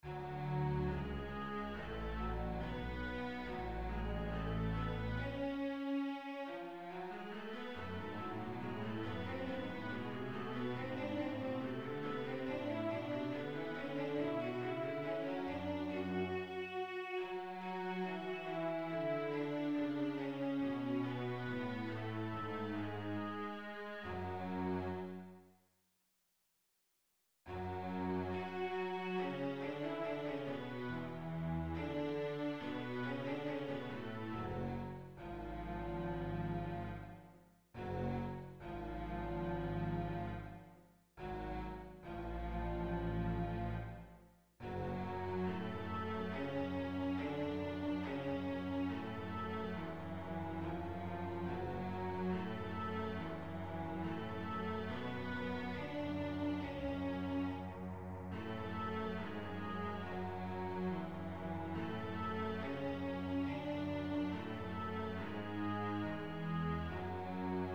Double Bass and Viola Duet
Recently I decided to compose a Contrabass and Viola duet for me and a friend of mine.